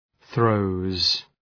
Προφορά
{ɵrəʋz}